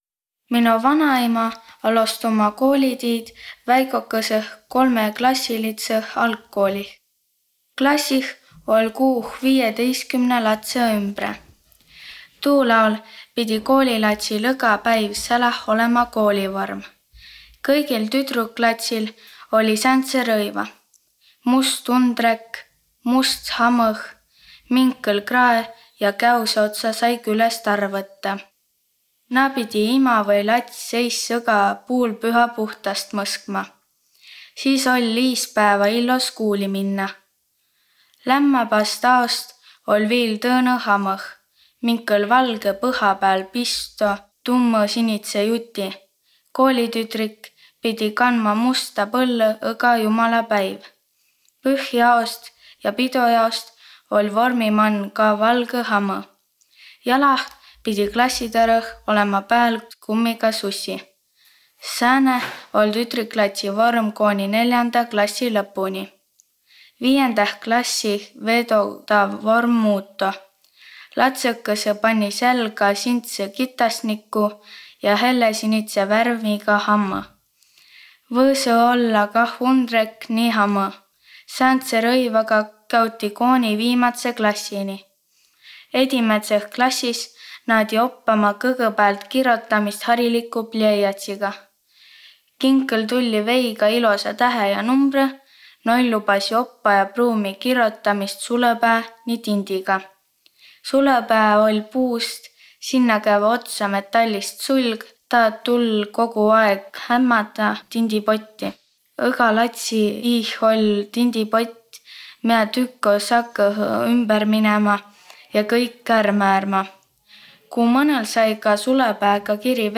Peri plaadilt juttõ ja laulõ seto aabitsa mano.